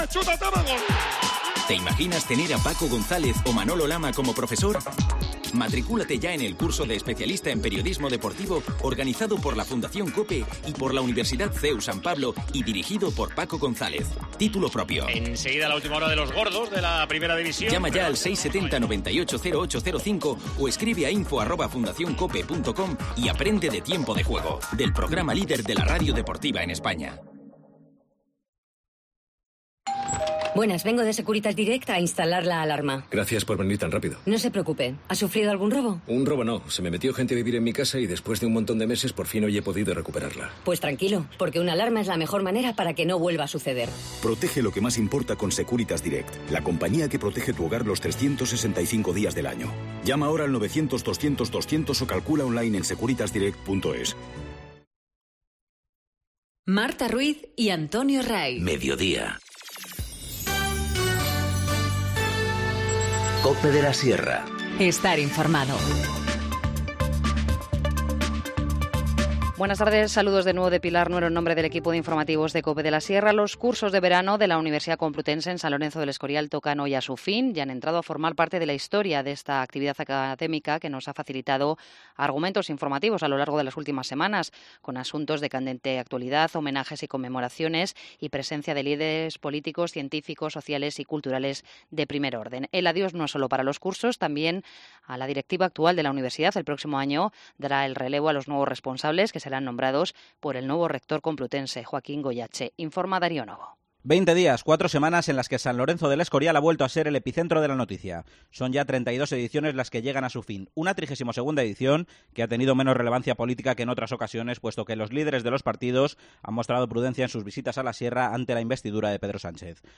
Informativo Mediodía 26 julio 14:50h